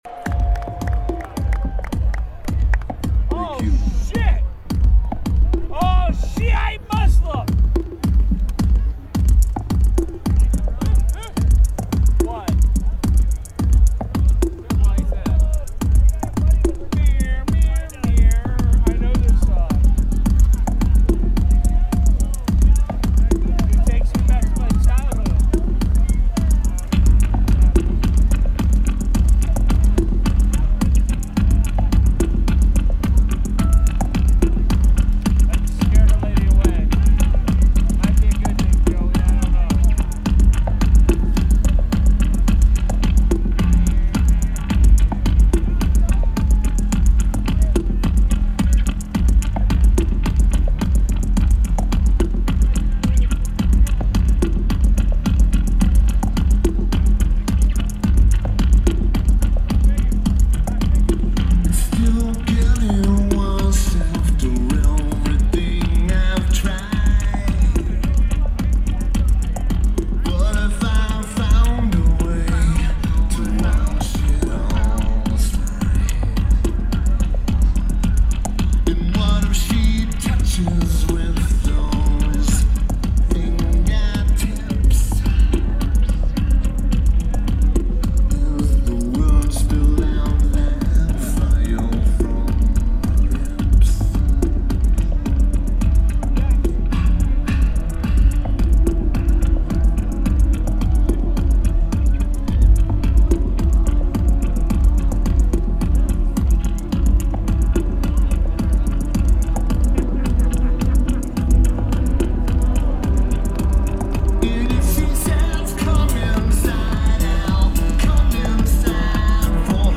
Louder Than Life Festival